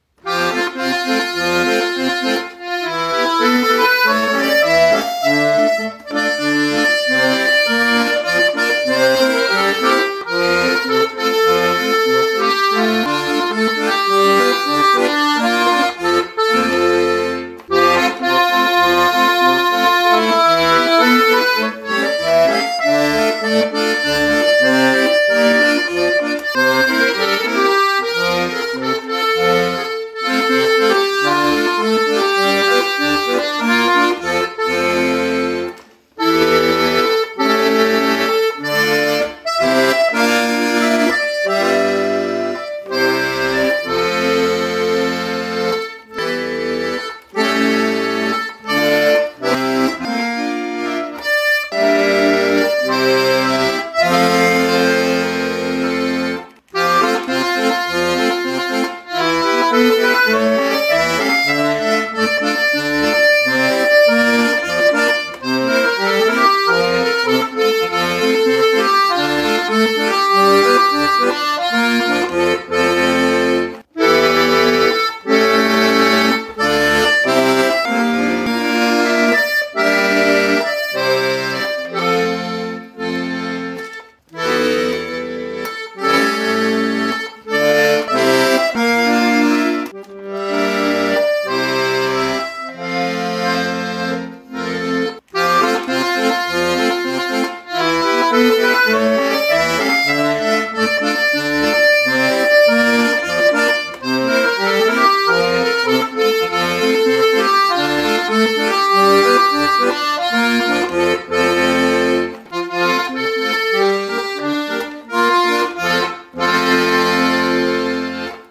Polkas